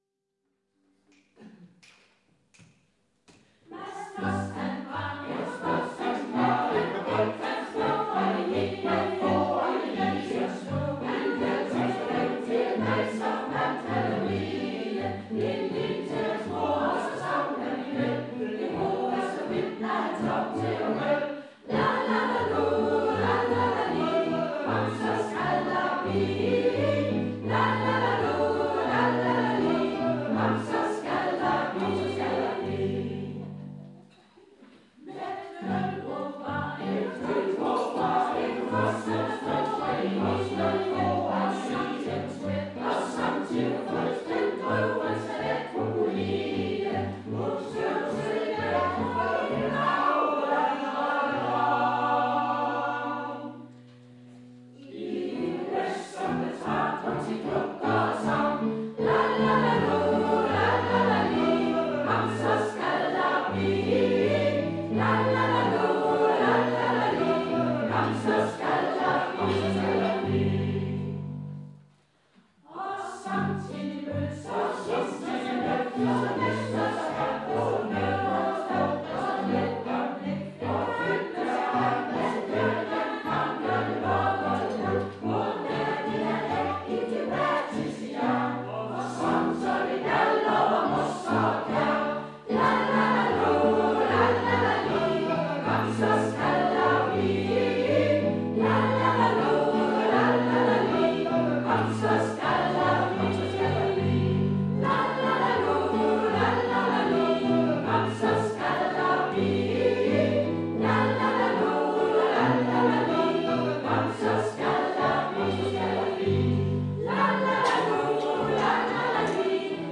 I det meste af Danmark markerer man i næste uge Spil Dansk-ugen, men Engesvang Musikforening og Kulturens Venner tyvstartede med en sangaften i kulturhuset Den Gamle Biograf.
18 sange blev det til. Dels sunget af musikforeningens kor, EKKO, og dels fællessange.